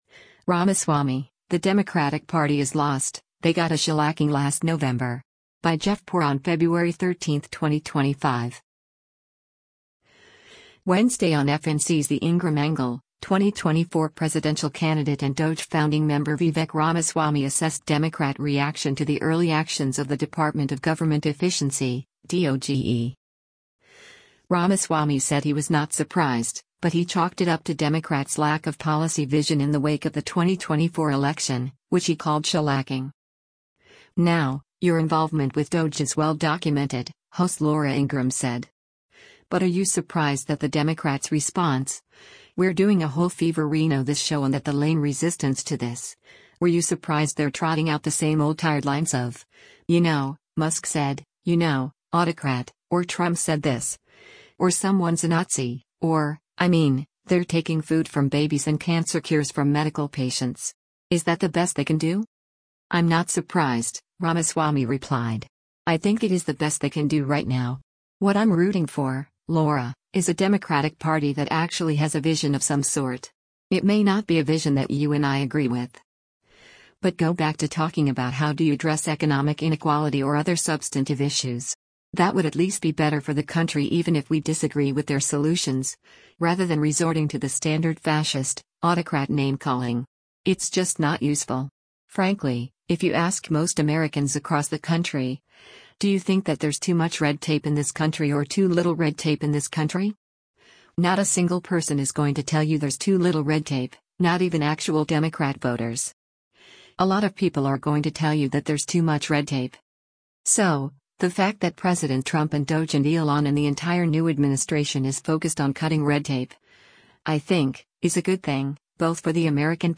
“Now, your involvement with DOGE is well documented,” host Laura Ingraham said.